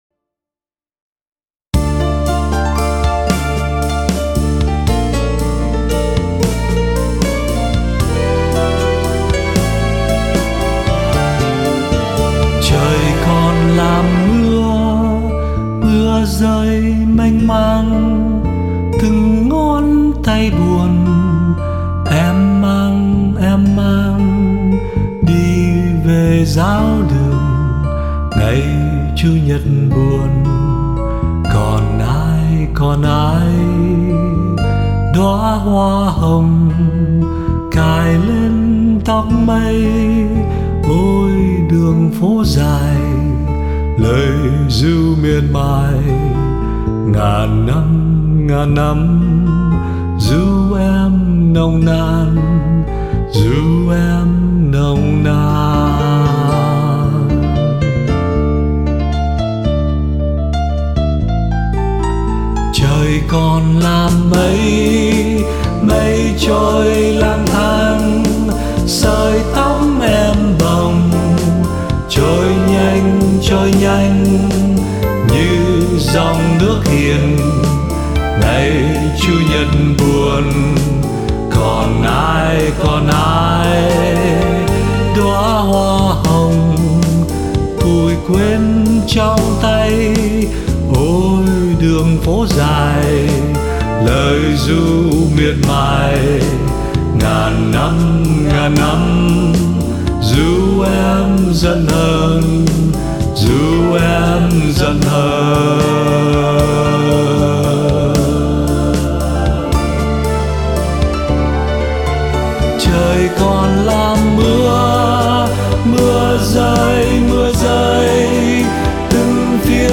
điệu slow waltz